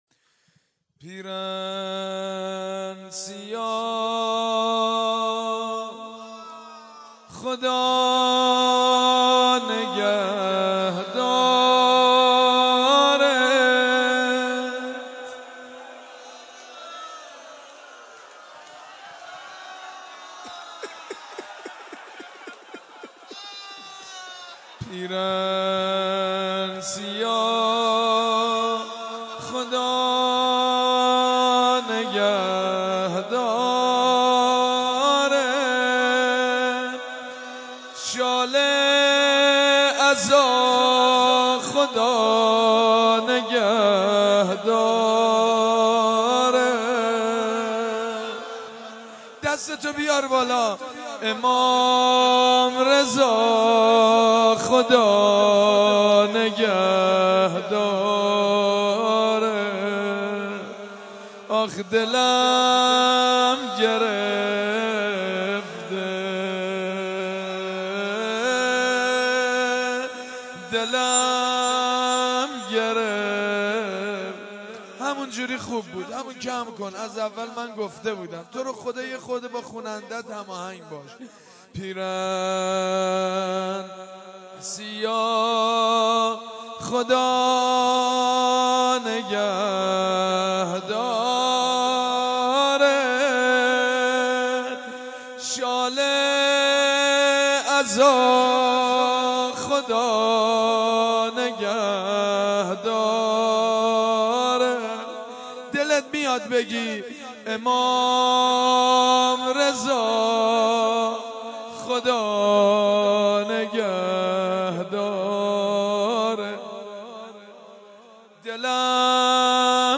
دانلود مداحی شال عزا، خدانگهدارت/ سید مجید بنی‌فاطمه